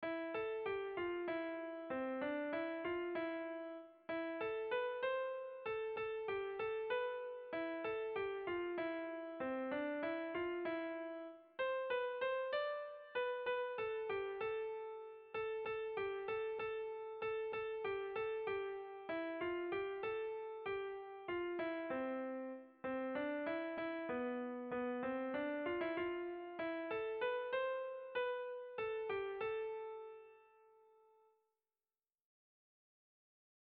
AA1A2BD